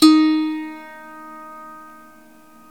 SWEDISH LY05.wav